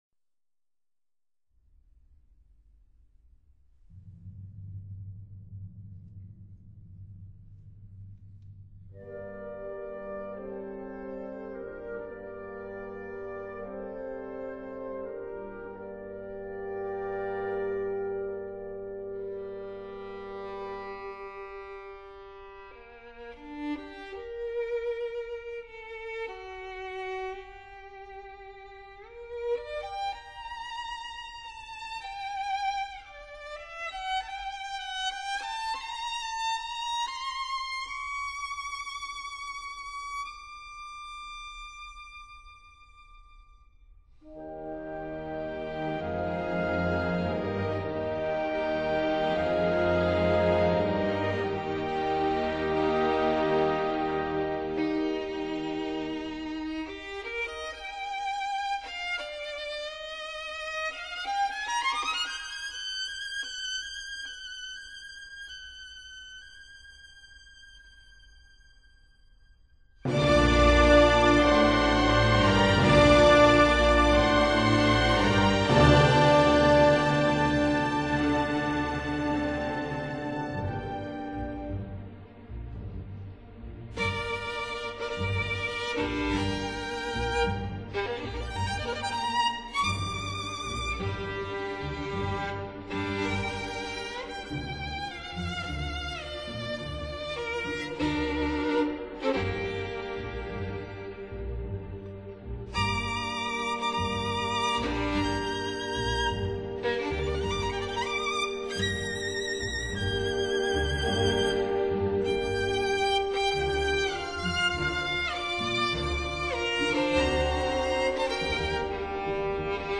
Genre: Classical, violin